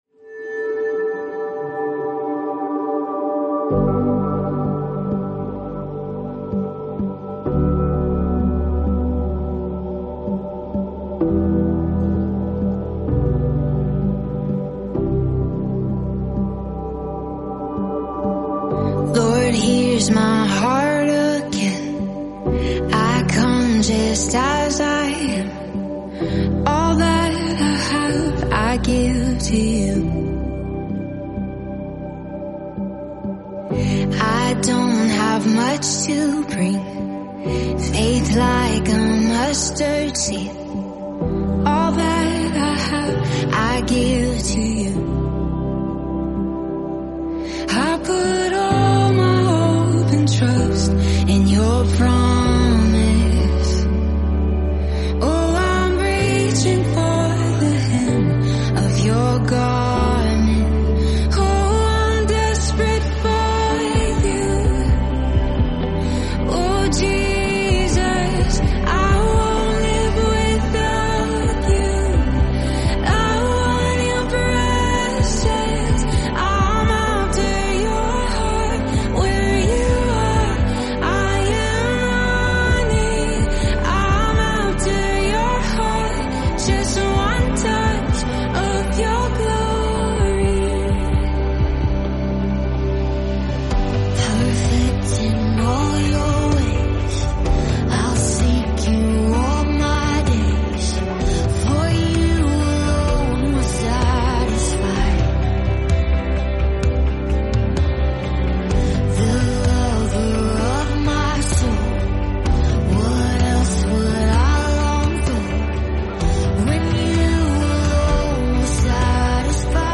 live worship album